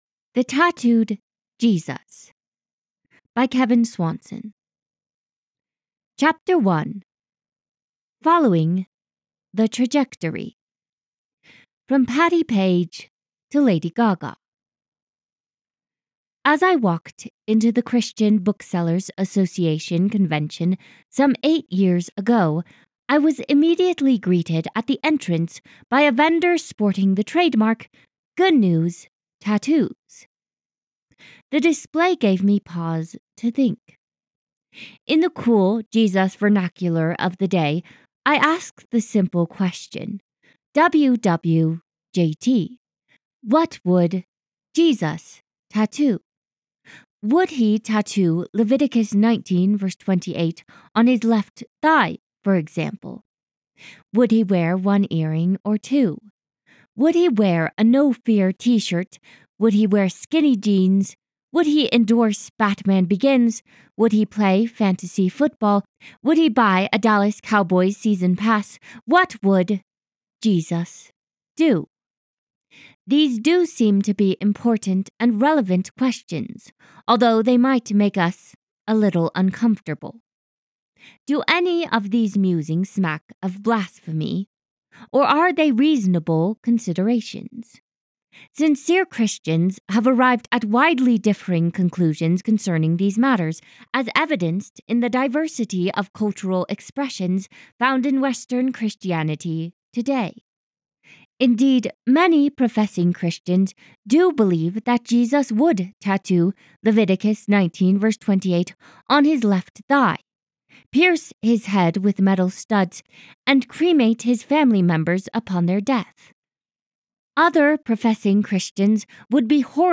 The Tattooed Jesus - Audiobook